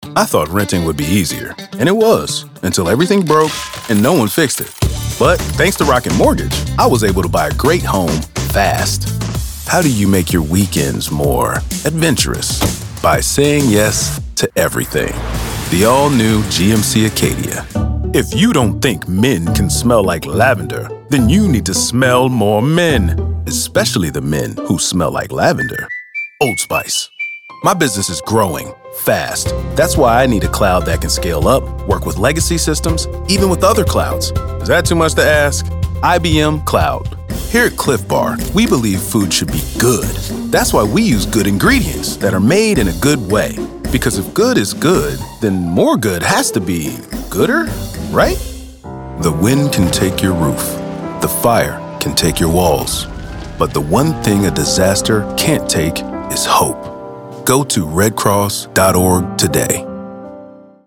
A dynamic, soulful, and soothing voice that is also energetic and engaging.
commercial demo
Commercial Demo.mp3